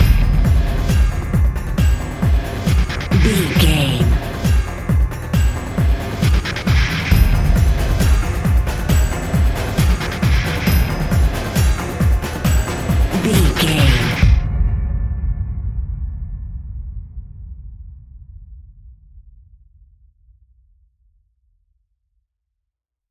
Atonal
Fast
tension
ominous
dark
eerie
driving
synthesiser
drums
drum machine